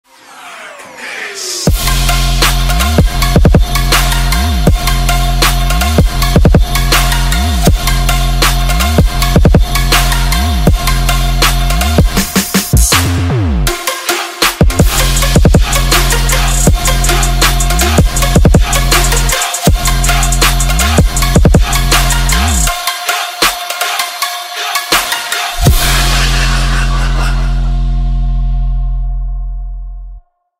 Стиль: Trap